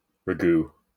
wymowa:
IPA/ɻæˈɡuː/ lub /ˈræɡuː/